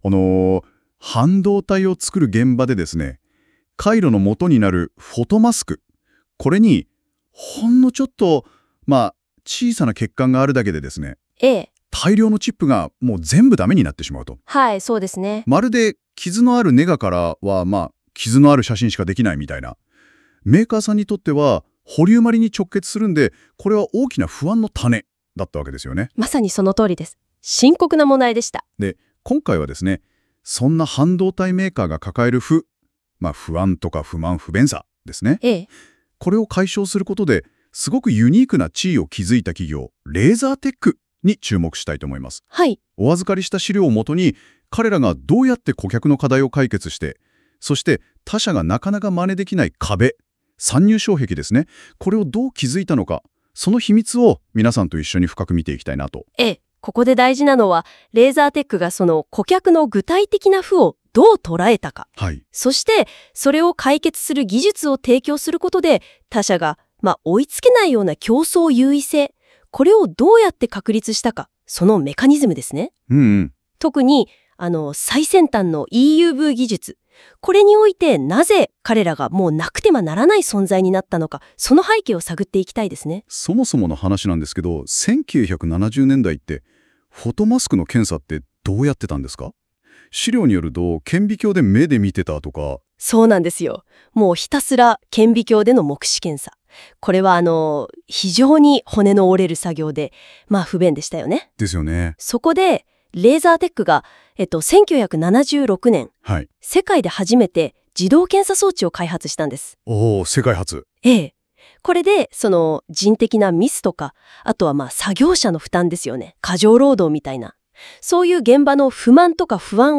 例えば、chatGPTと一緒にレーザーテックについて調べていたのですが、その調査結果をNotebookLMに読み取らせると、こんな音声ファイルが返ってきました：